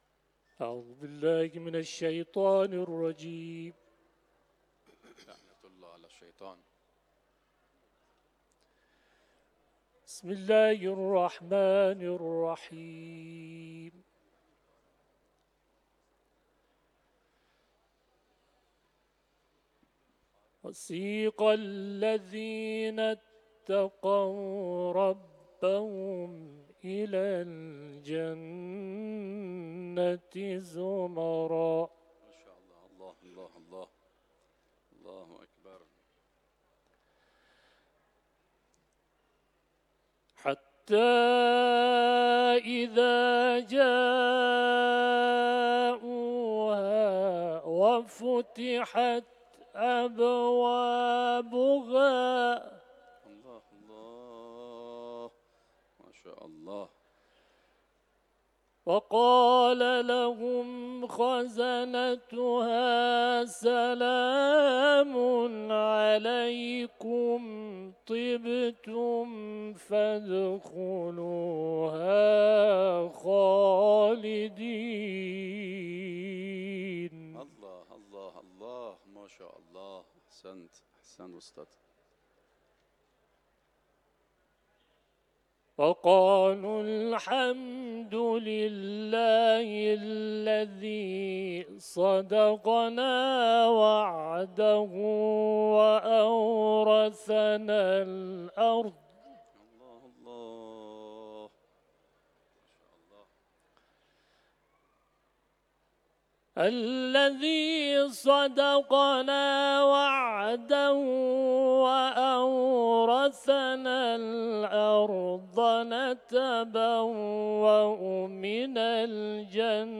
تلاوت
حرم مطهر رضوی ، سوره زمر